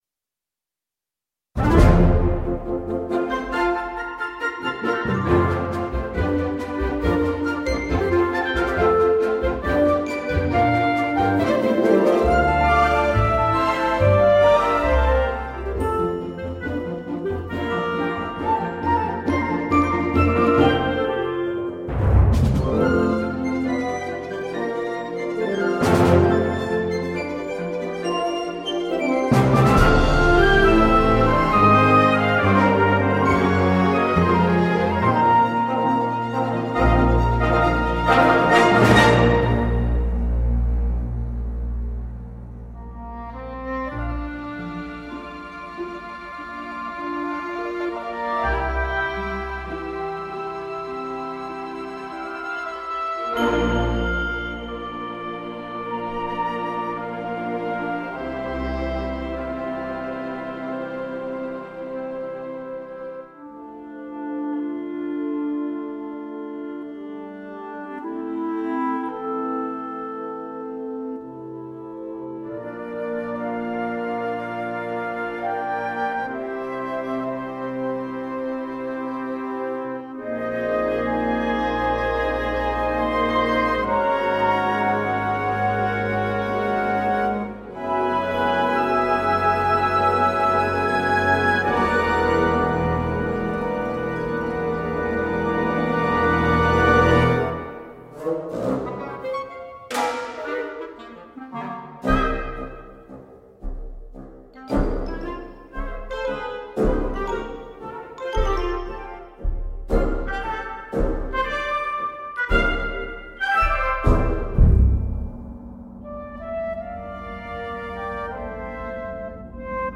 旗舰木管乐器
在Teldex得分舞台上录制，声音自然，圆润，并与其他柏林系列完美融合。
所有乐器都以相同的自然增益水平精心录制，音乐家位于传统管弦乐队的座位位置。